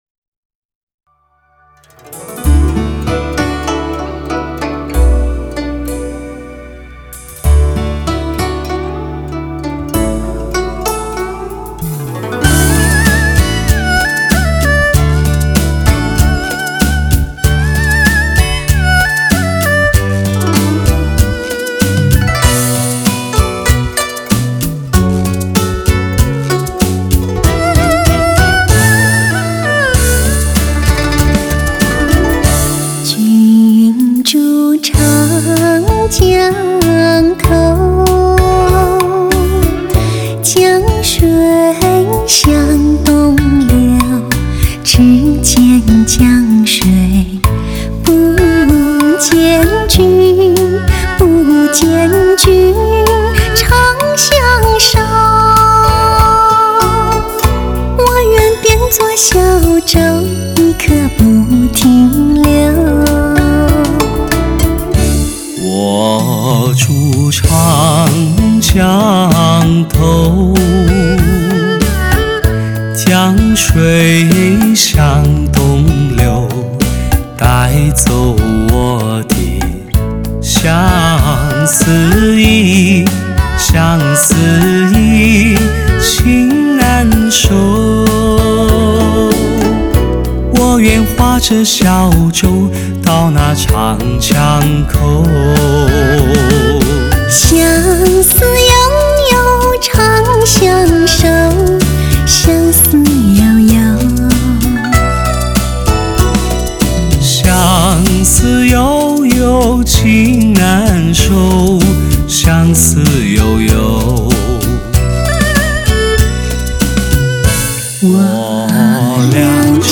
那富有磁性饱满浑厚的声音。
如吟如诉的声音让人沉醉其中，梦牵魂移，久久不能忘怀。
难得亳无匠气而且流畅自然的巨作，乐章温暖实在录音丰满温醇